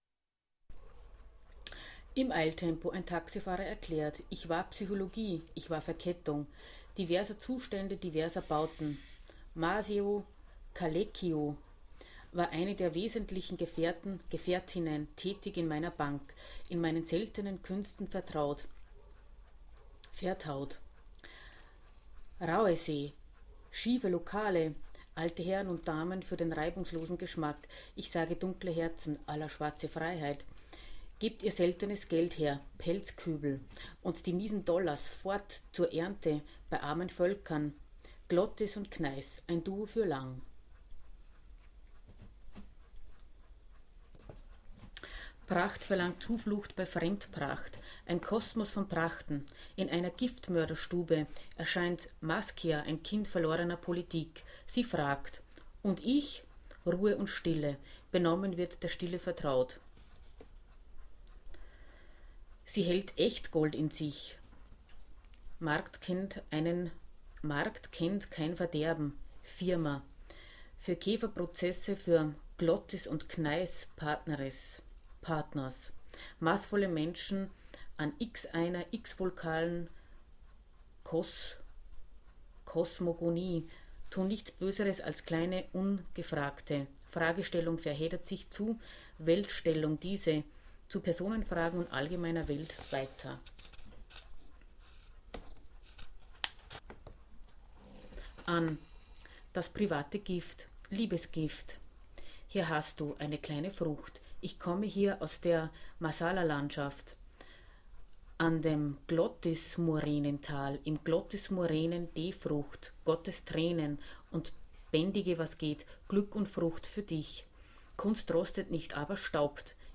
Fremdidiome inkorporieren: Die unbeleckte Aussprache ist für mich wesentlich, die Sprecher und Stimmen treten hervor.